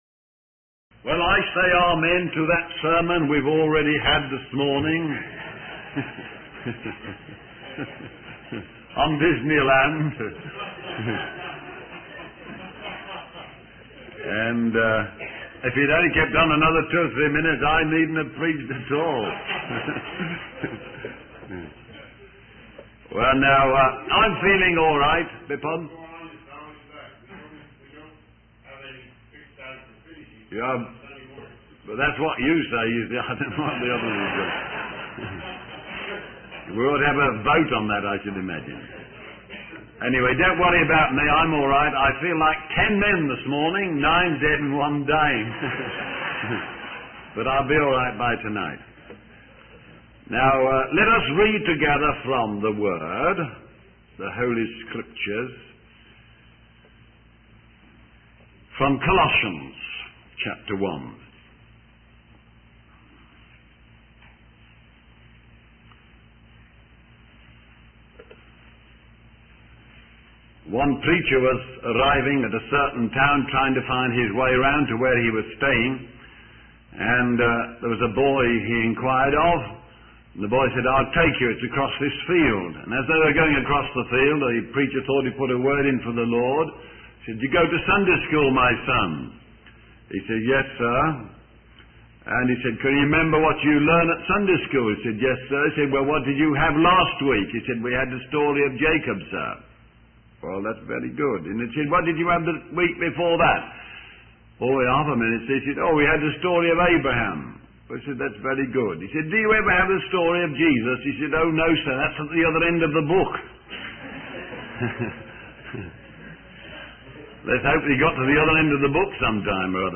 The video is a sermon on the book of Colossians, focusing on the introduction, the Christ, the church, and the conclusion.